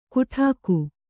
♪ kuṭhāku